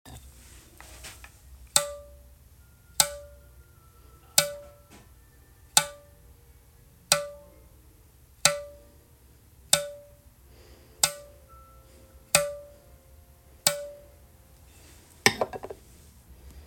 We have been calculating dwell time for many years, so below we will associate a frequency (sound) with string bed stiffness so you can hear what “Pop” sounds and feels like!